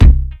Kick8.wav